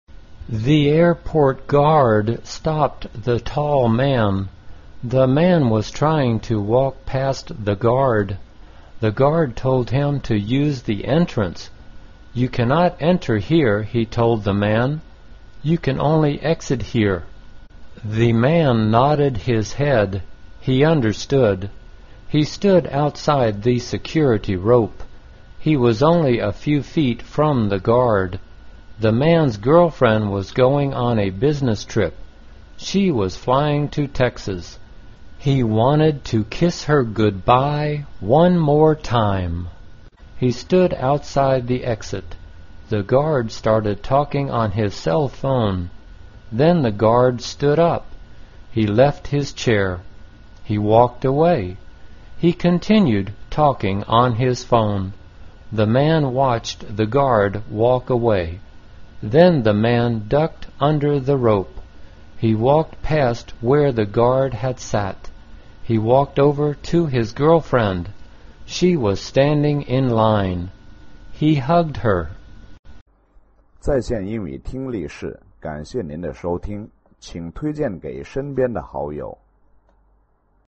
简单慢速英语阅读：Airport "Security" (1) 听力文件下载—在线英语听力室